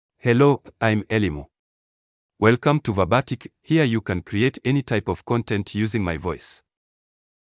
ElimuMale English AI voice
Elimu is a male AI voice for English (Tanzania).
Voice sample
Male